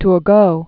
(tr-gō, tür-), Anne Robert Jacques 1727-1781.